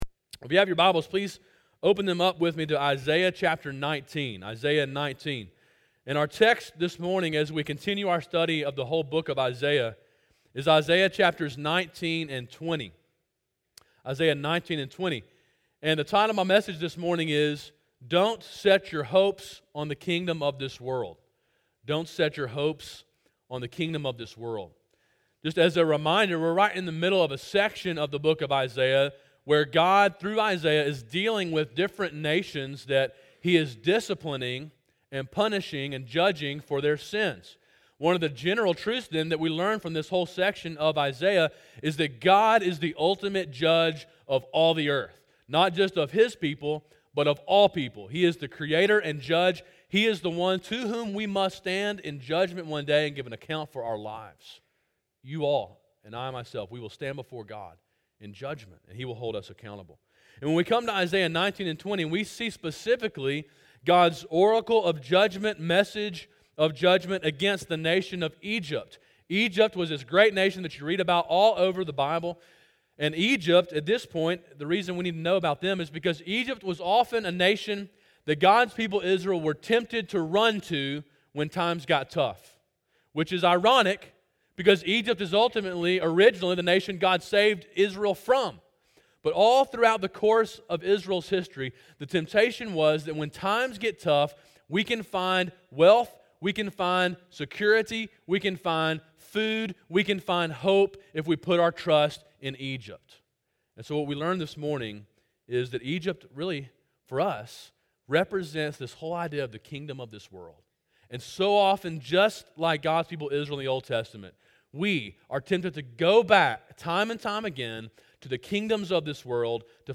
Sermon in a series on the book of Isaiah.
sermon-1-29-17.mp3